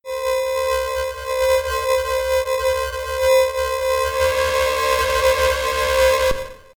I’m still getting sound issues with the old OscBankSynth which I’m using as a main synth on a new project. (do turn your volume way down ) /uploads/default/original/2X/7/78d83d3349e4434ebfa24071d60b1f5ae008f737.mp3